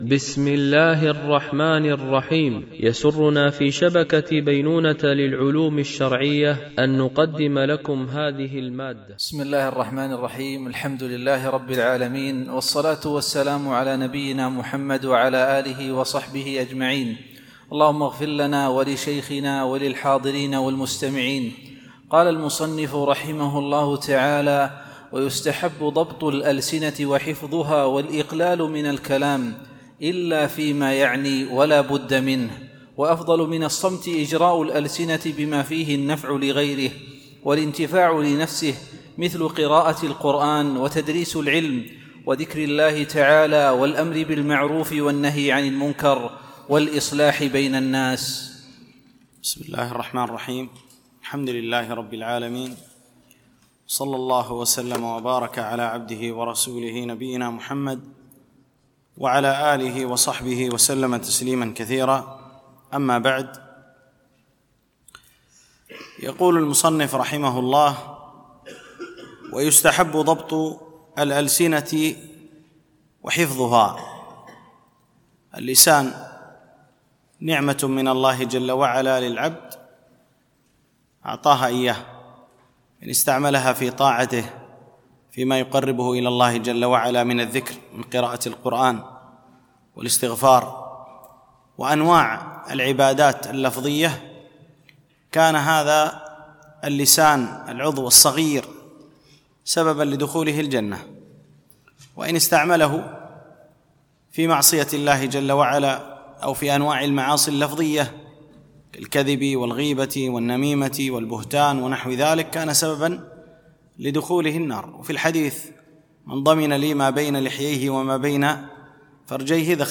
بمسجد عائشة أم المؤمنين - دبي (القوز 4)